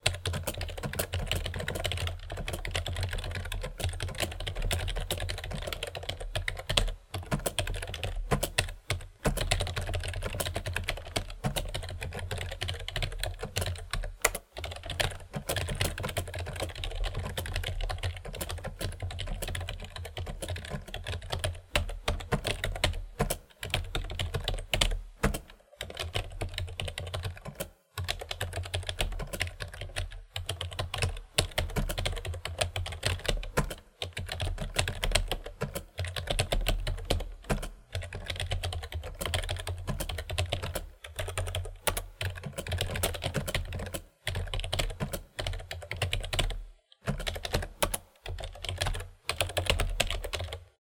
me_typing
office typing sound effect free sound royalty free Memes